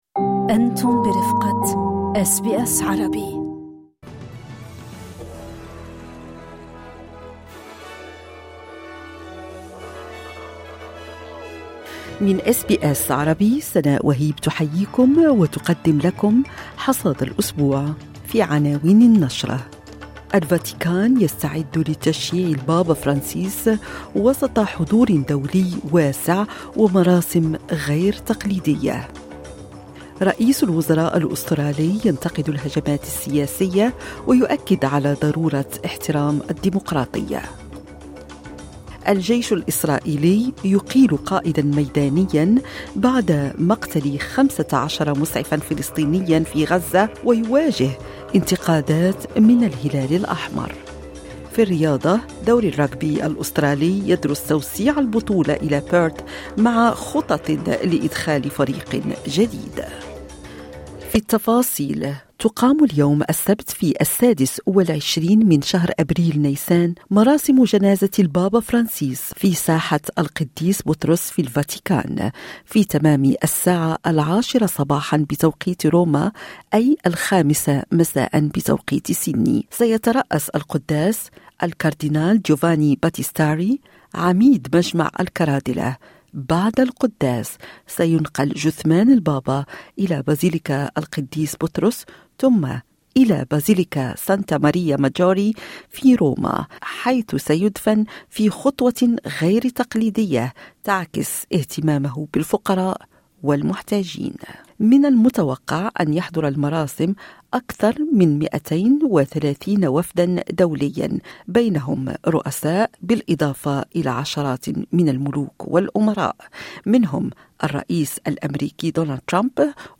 يمكنكم الاستماع الى النشرة الاخبارية كاملة بالضغط على التسجيل الصوتي أعلاه.